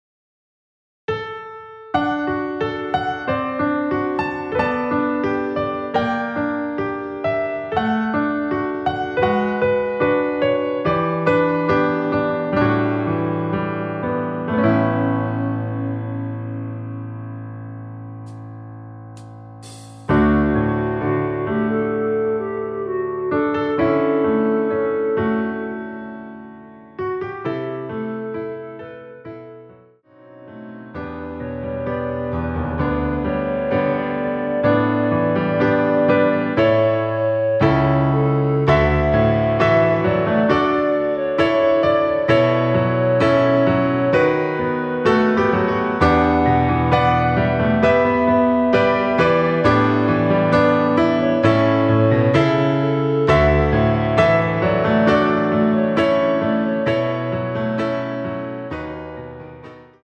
원키 멜로디 포함된 MR 입니다.(미리듣기 참조)
D
멜로디 MR이라고 합니다.
앞부분30초, 뒷부분30초씩 편집해서 올려 드리고 있습니다.
중간에 음이 끈어지고 다시 나오는 이유는